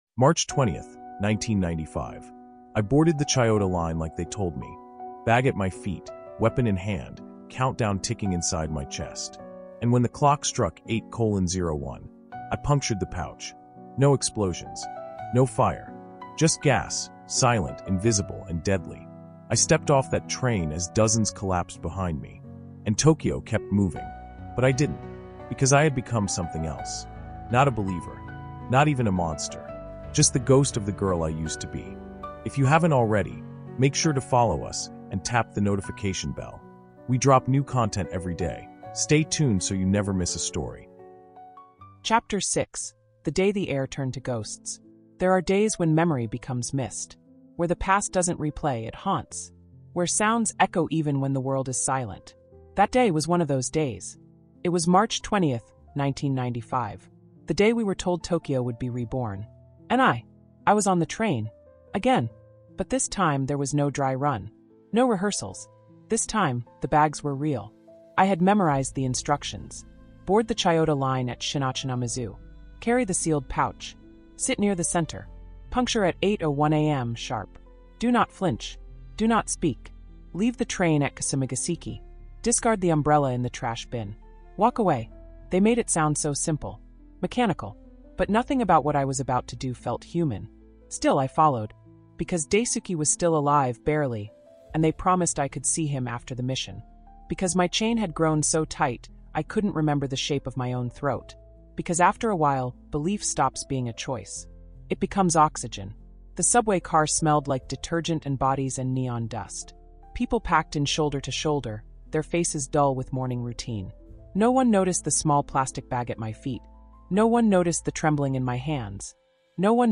Terror in Tokyo Subway Chapter Six | The Day the Air Turned to Ghosts | Audiobook